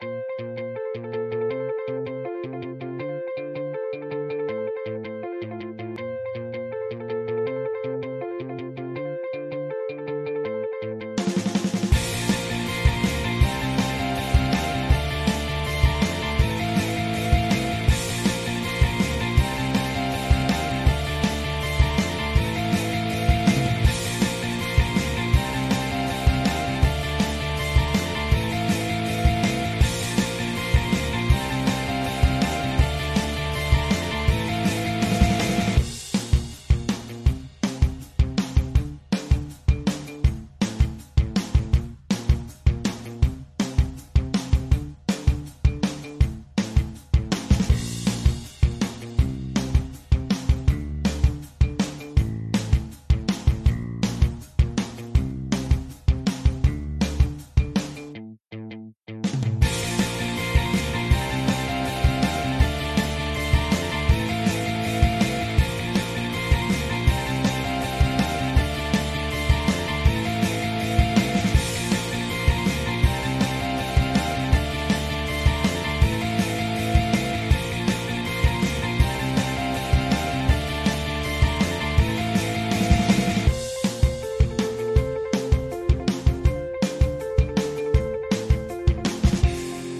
Rock_0322_5.mp3